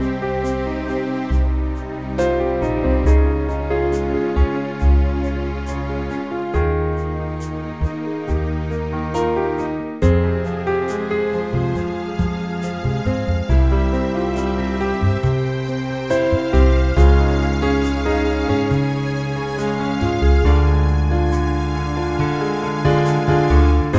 Original Male Key